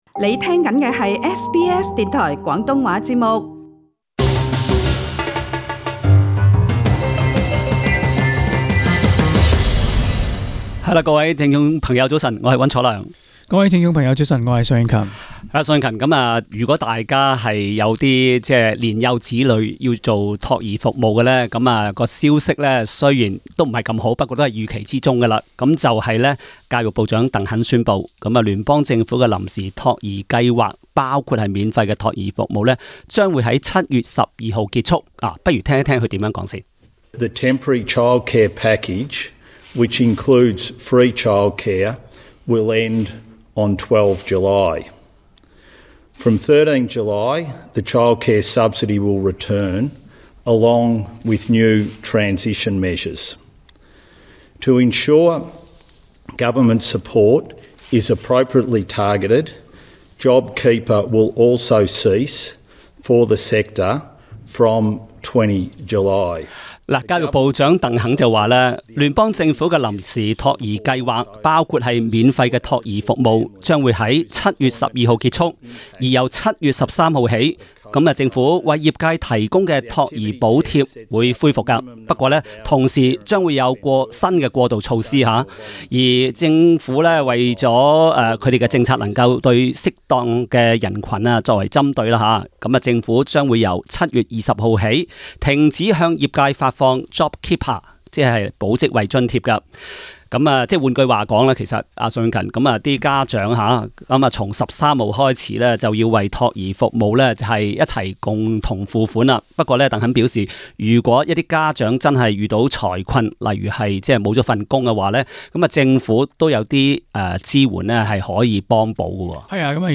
Source: AAP SBS广东话播客 View Podcast Series Follow and Subscribe Apple Podcasts YouTube Spotify Download (11.99MB) Download the SBS Audio app Available on iOS and Android 在新冠疫情肆虐期间，很多家长都取消使用托儿服务，令到很多托儿中心面对结业危机。